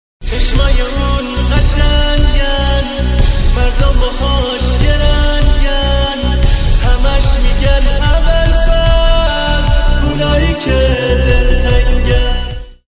زنگ موبایل
رینگتون احساسی و با کلام